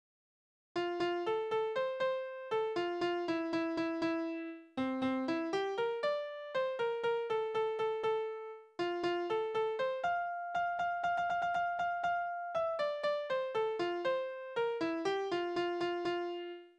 Tonart: F-Dur
Taktart: 2/4
Tonumfang: kleine None
Besetzung: vokal